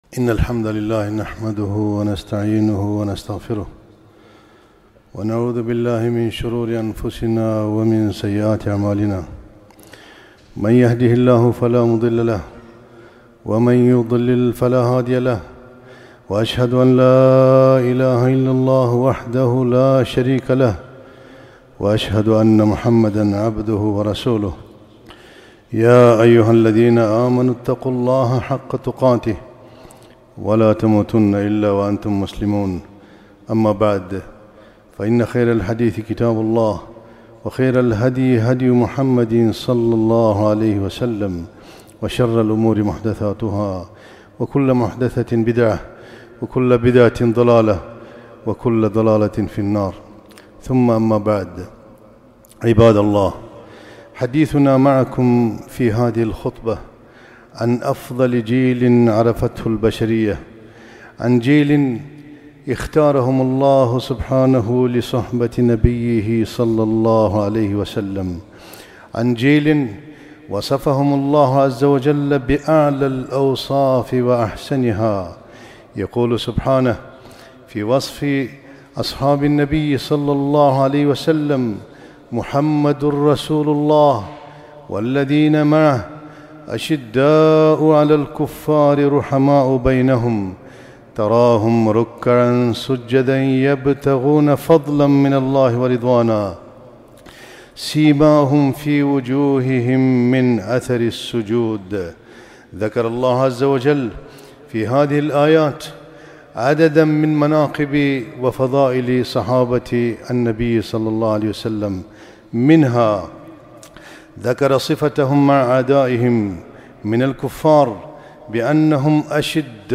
خطبة - مكانة الصحابة رضي الله عنه